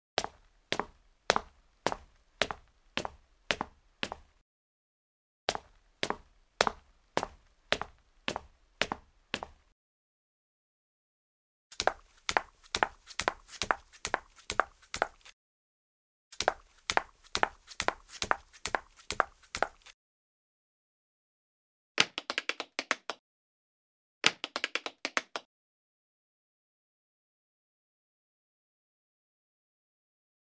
高跟鞋的行走－YS070525.mp3
通用动作/01人物/01移动状态/高跟鞋/高跟鞋的行走－YS070525.mp3